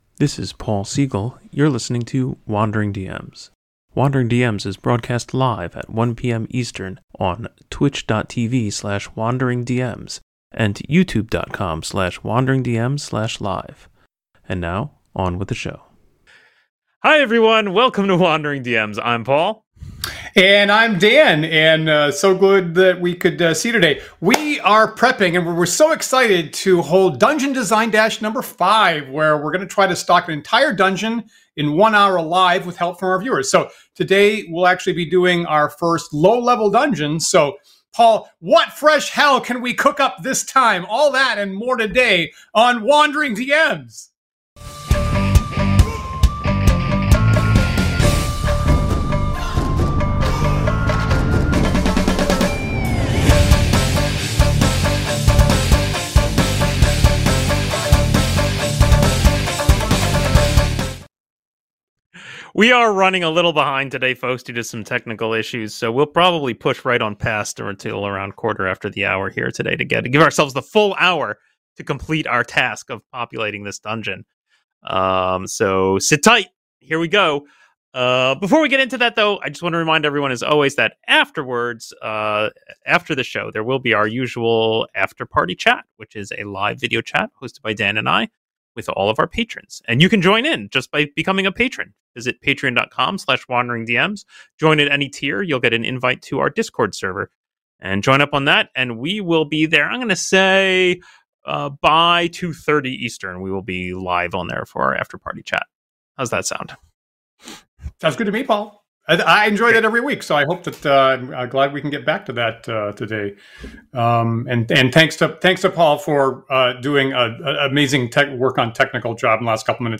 Armed with only their D&D source books, Matt Finch’s excellent Tome of Adventure Design, and the input from live viewers, they’ll create a fully stocked and playable D&D dungeon adventure right in front of your eyes.